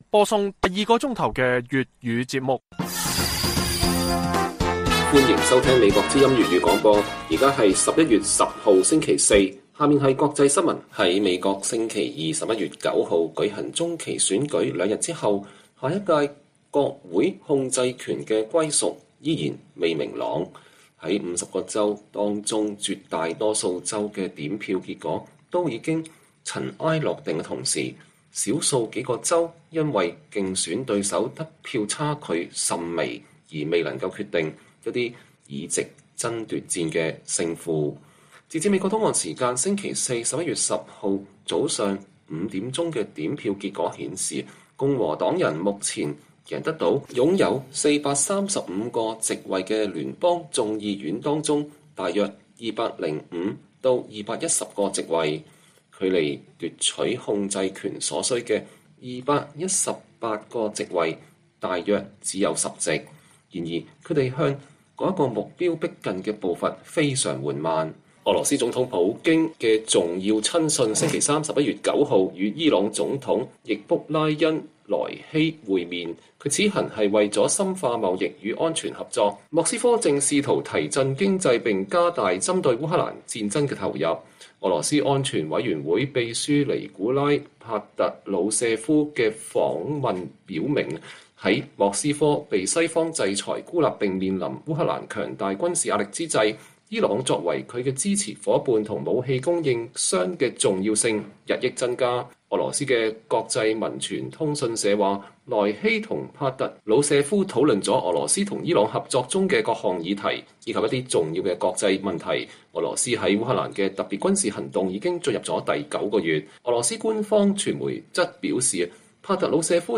粵語新聞 晚上10-11點: 國會控制權歸屬仍不明朗，拜登承諾會跨黨合作